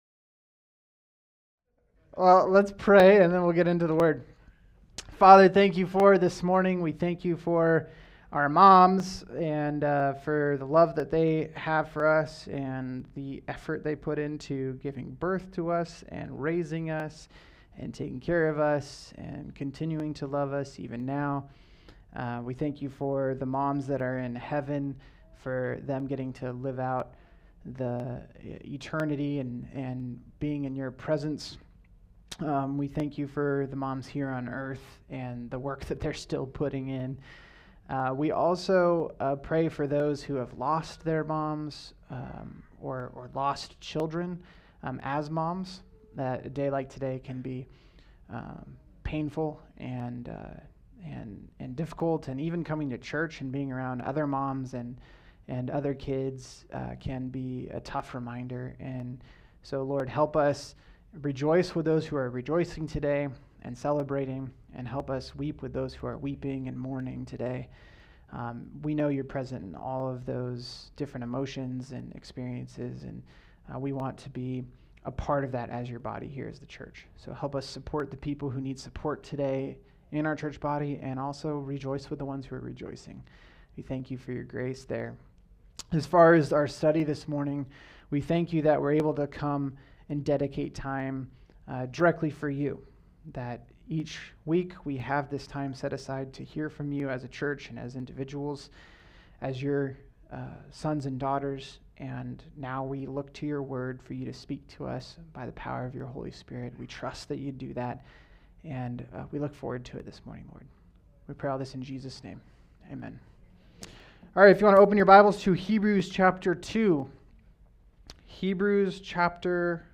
All Sermons
the Greatest Topic: Sunday Morning 2025 Book